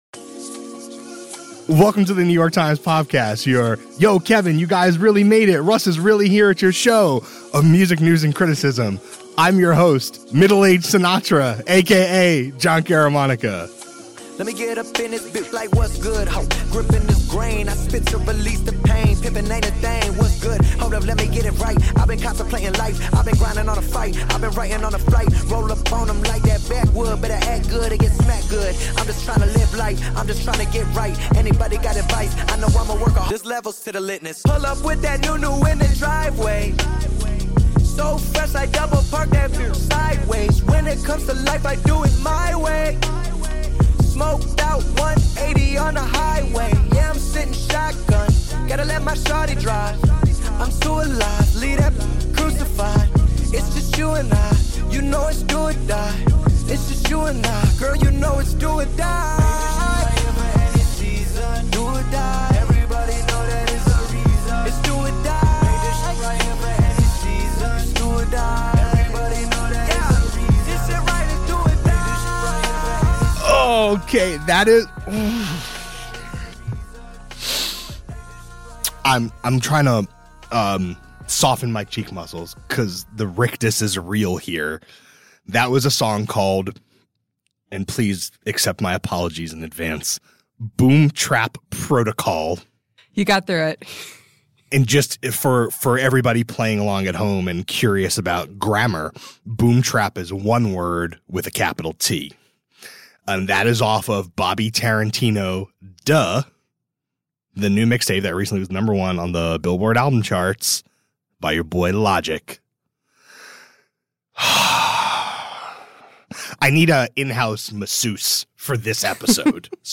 A conversation about the earnest rapper and the SoundCloud renegade, both successes of modern internet-driven rap fandom.